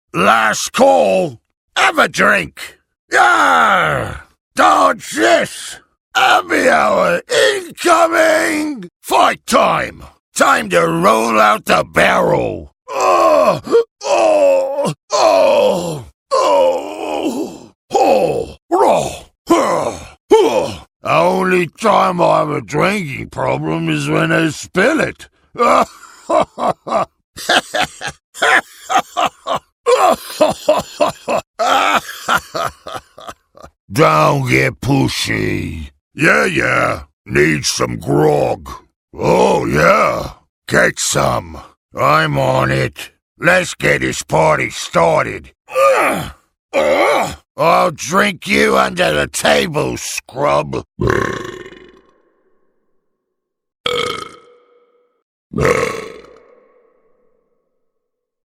Gragas / Gragas Voice - English - League of Legends(MP3_160K)_120114.mp3